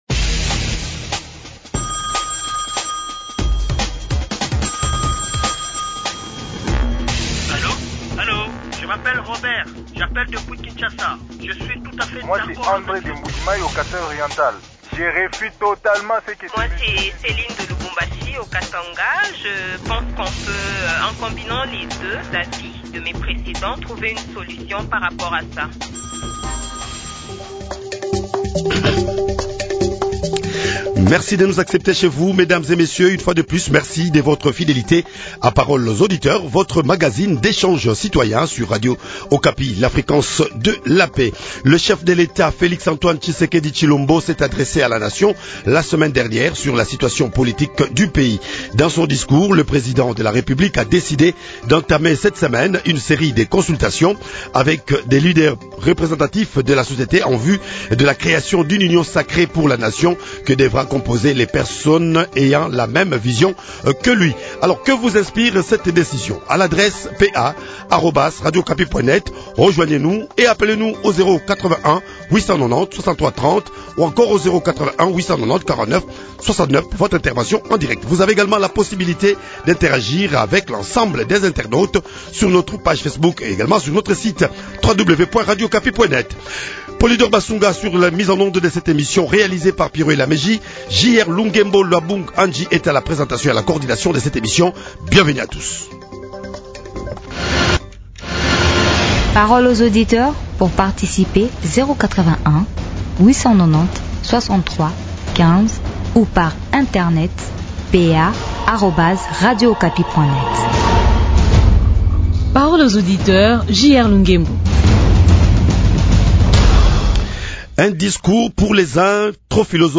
Pour participer à ce débat, communiquez-nous votre numéro de téléphone en bas de votre commentaire.